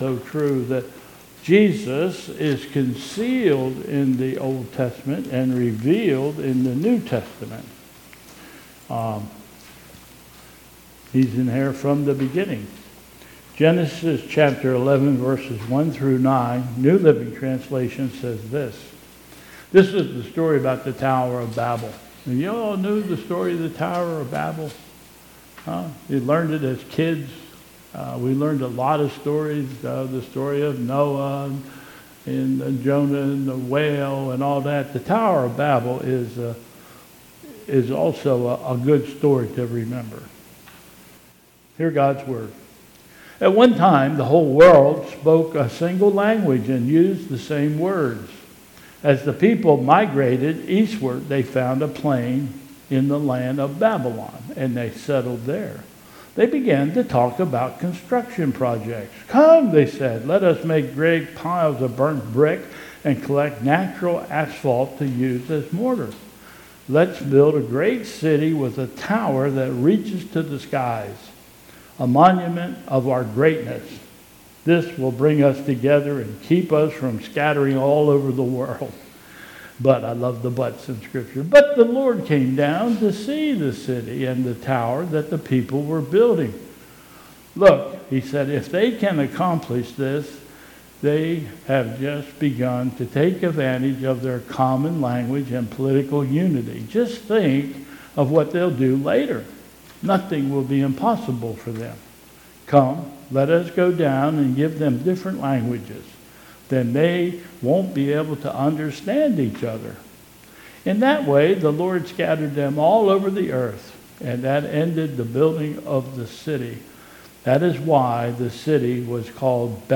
2022 Bethel Covid Time Service
Call to Worship...
...Apostle's Creed...and...Gloria Patris